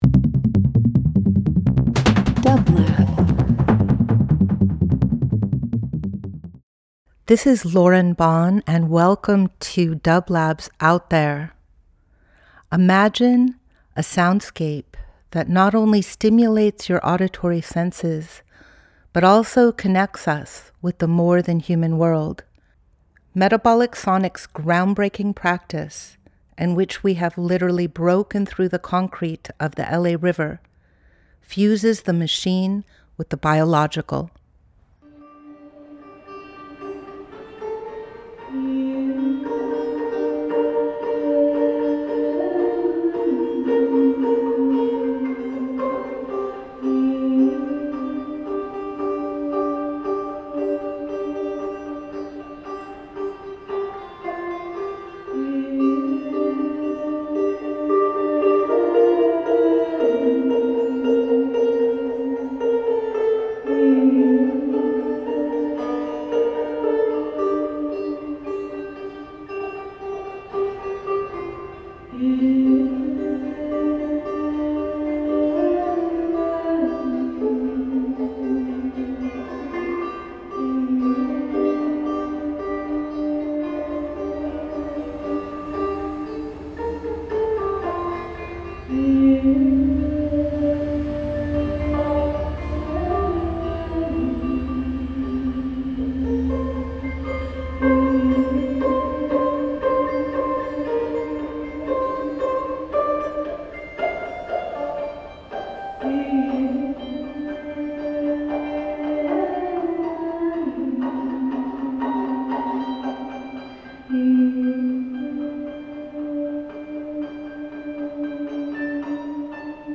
Each week we present field recordings that will transport you through the power of sound.
Metabolic Sonics Metabolic Studio Out There ~ a field recording program 12.11.25 Field Recording Instrumental Sound Art Voyage with dublab into new worlds.
Today we are sharing Metabolic Sonics jamming an accompaniment to the REIMAGINE printmaking gathering at Metabolic Studio on December 1, 2025.